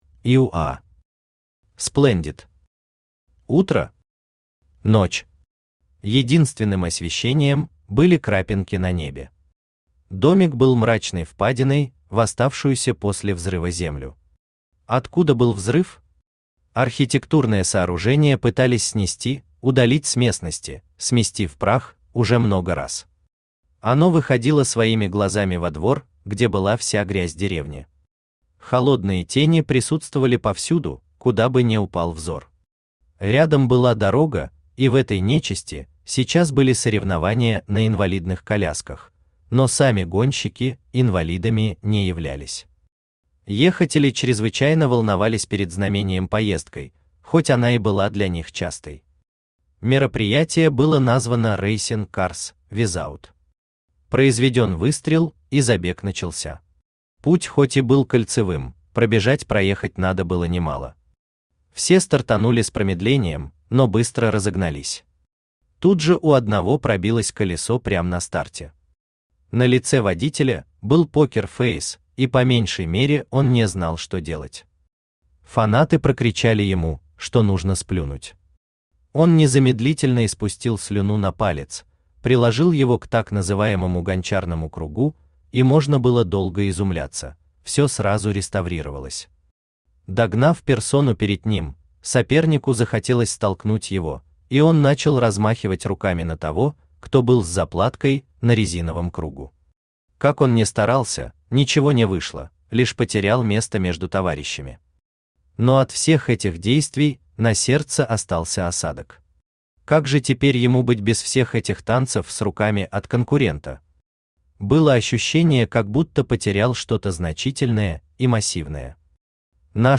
Аудиокнига Splendid | Библиотека аудиокниг
Aудиокнига Splendid Автор Ио Уа Оа Читает аудиокнигу Авточтец ЛитРес.